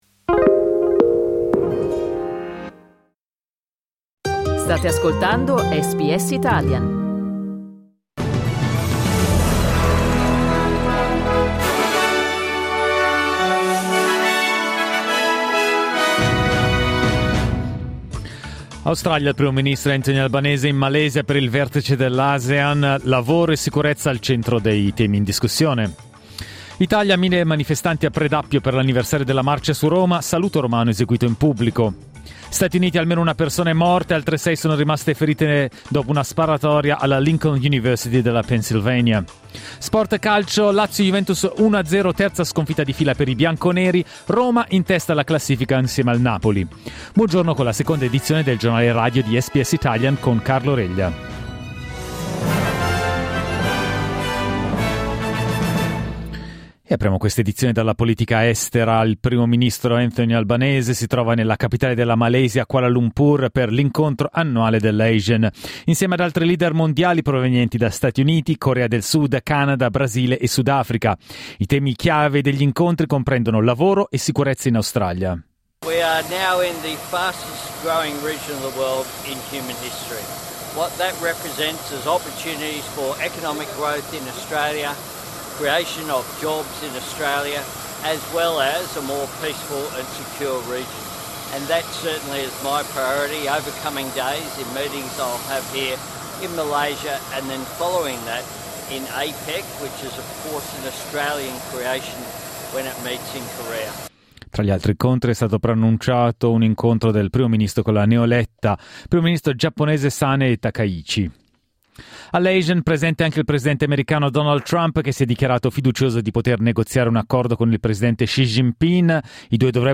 Giornale radio lunedì 27 ottobre 2025
Il notiziario di SBS in italiano.